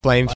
flame
Category: Animals/Nature   Right: Personal